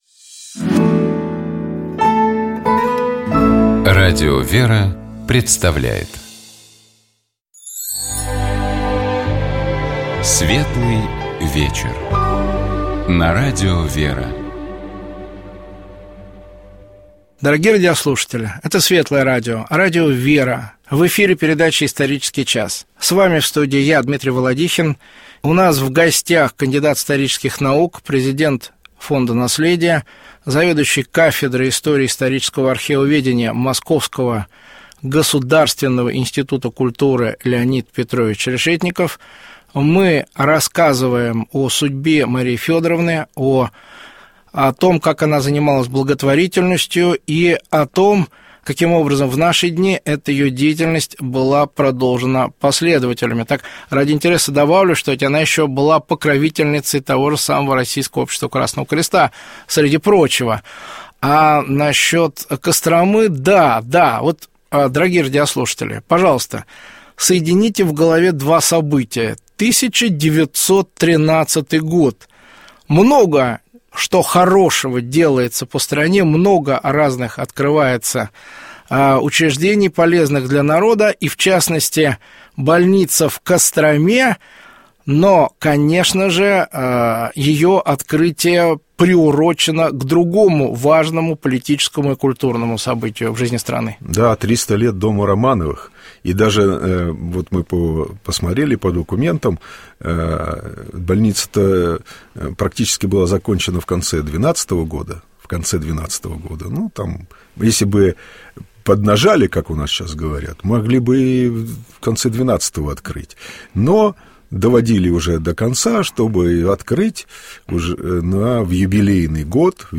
У нас в гостях был кандидат исторических наук